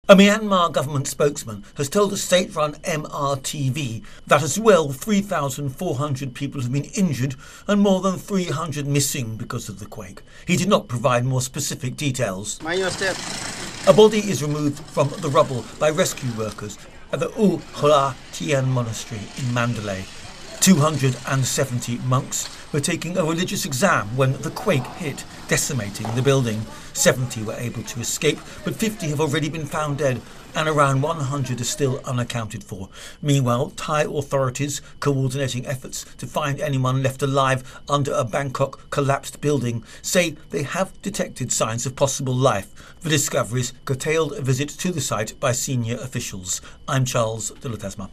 The death toll from the 7.7 magnitude earthquake that hit Myanmar has passed 1,700 as more bodies have been pulled from the rubble. ((Myanmar is pronounced mee-AHN'-mar))